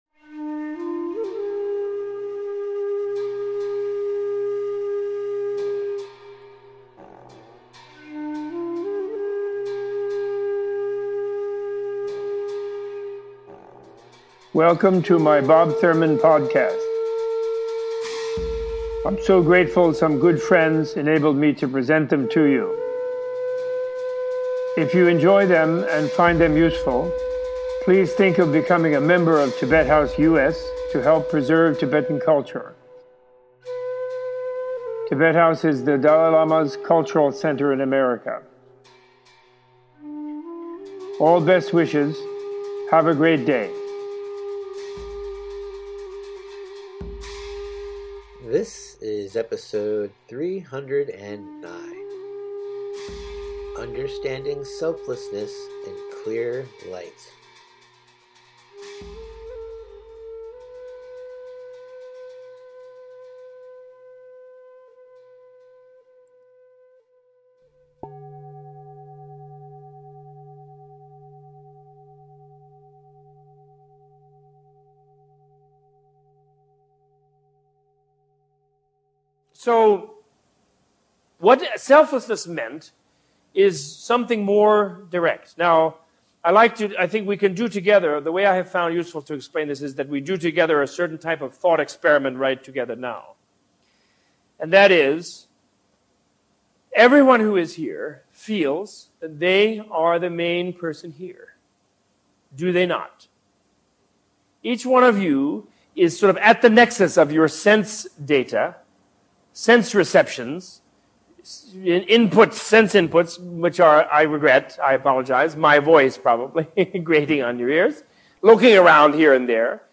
Opening with guided meditation on searching for the self, Robert Thurman gives a teaching on self, selflessness and how to understand what clearlight means from the Buddhist perspective.
-Text From Better Listen Basic Buddhism This episode is an excerpt from the Better Listen “Basic Buddhism” Audio Course.